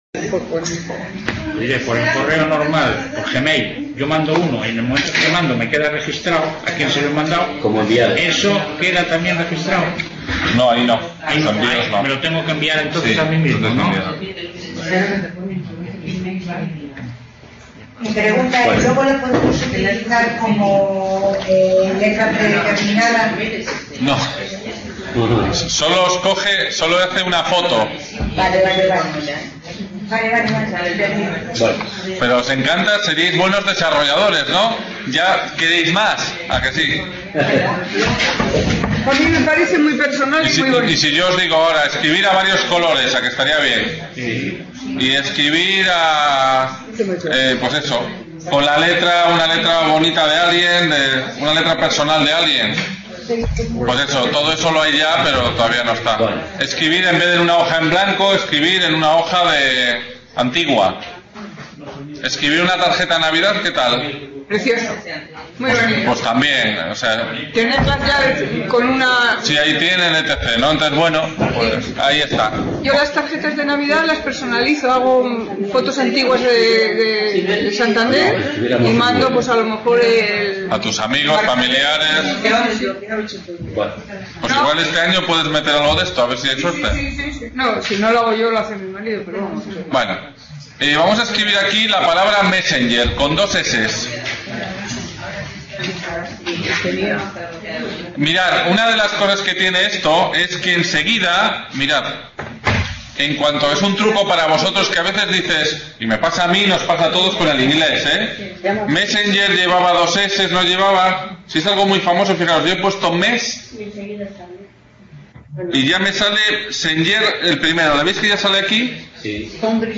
Reunion, debate, coloquio...